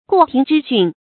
過庭之訓 注音： ㄍㄨㄛˋ ㄊㄧㄥˊ ㄓㄧ ㄒㄩㄣˋ 讀音讀法： 意思解釋： 用以指父親的教誨。